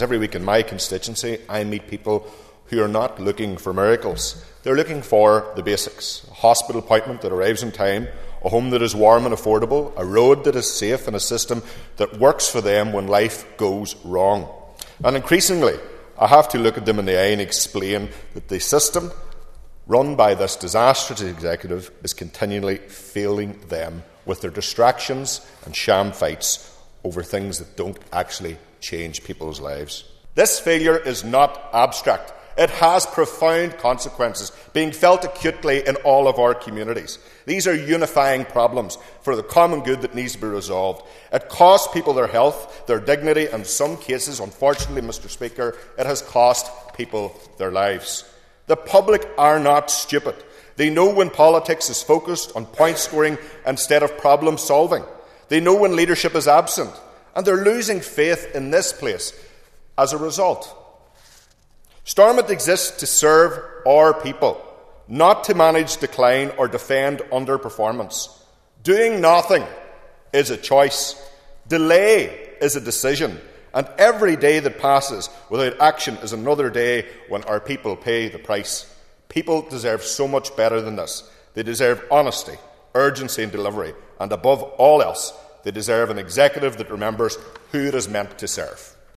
That’s the belief of West Tyrone MLA Daniel McCrossan, who told the Assembly this week that while people are facing delays in the health service, lack of adequate housing, a cost of living crisis, and other real challenges, the focus of the Executive seems to be on political point scoring.